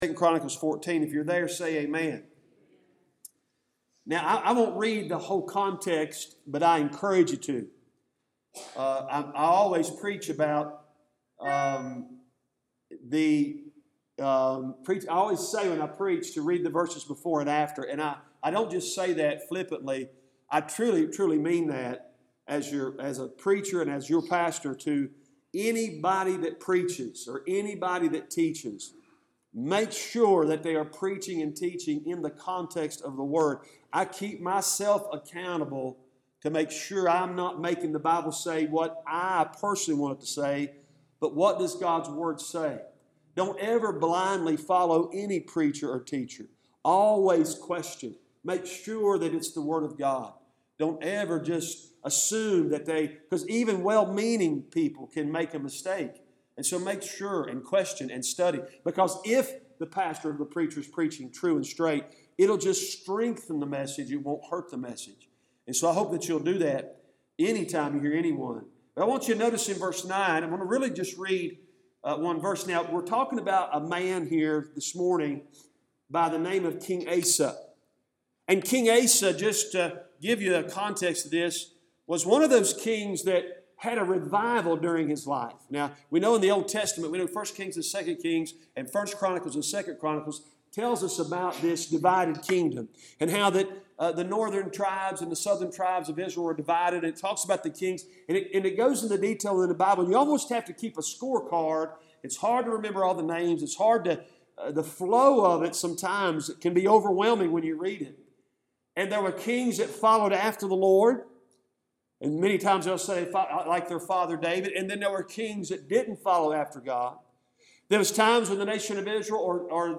Cooks Chapel Baptist Church Sermons